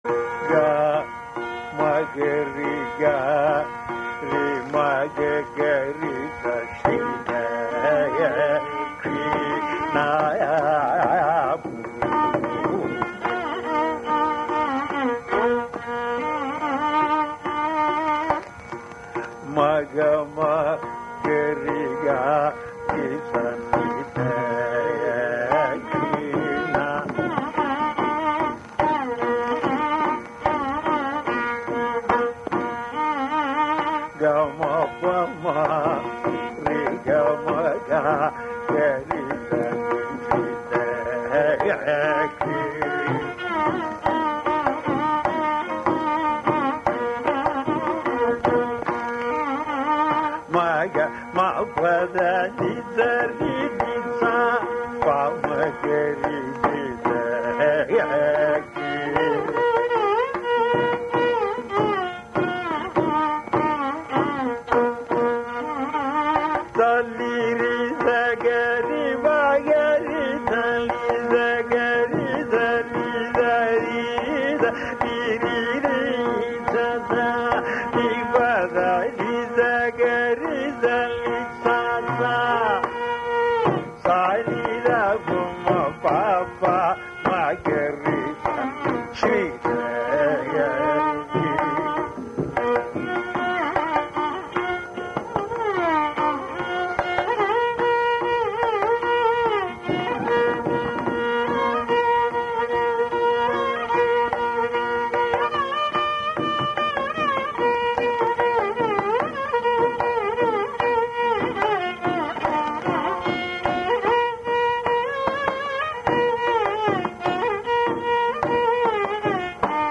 06d-dakshinamurthe-shankarabharanam-swarakalpana.mp3